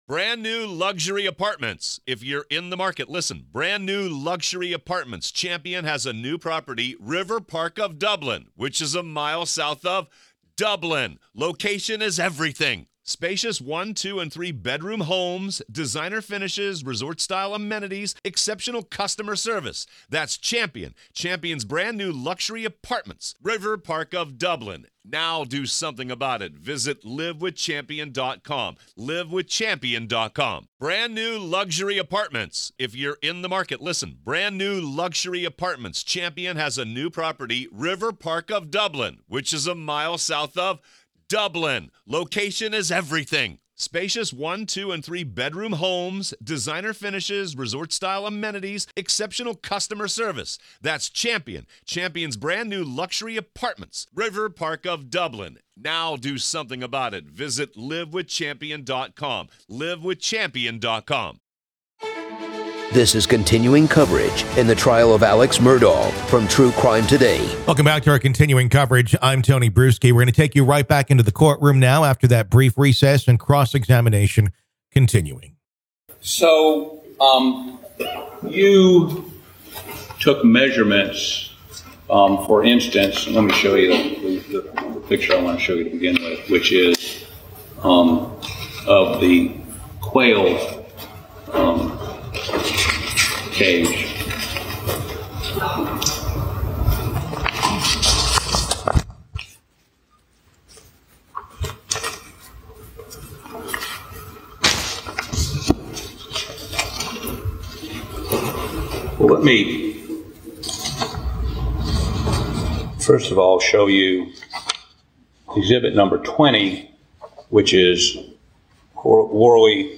The Trial Of Alex Murdaugh | FULL TRIAL COVERAGE Day 4 - Part 2